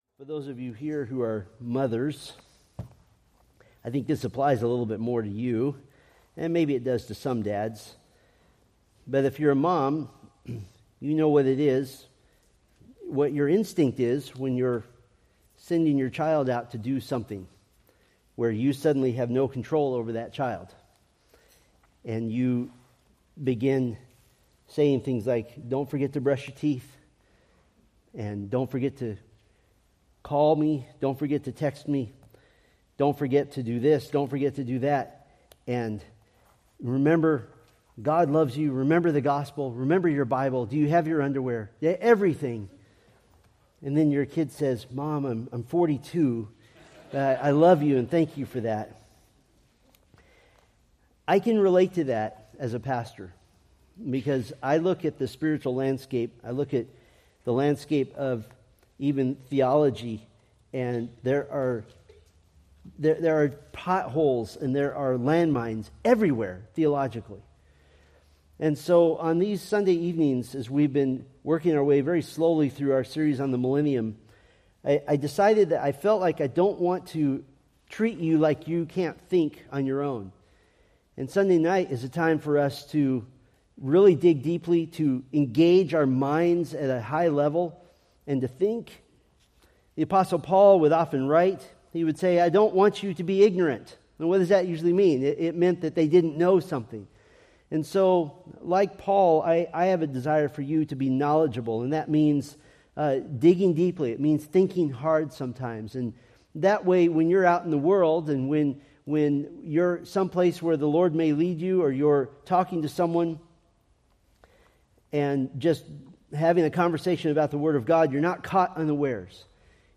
Preached February 9, 2025 from Selected Scriptures